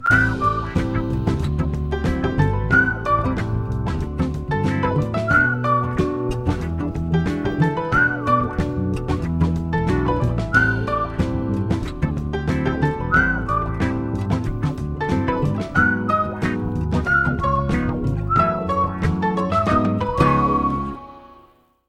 Стандартный рингтон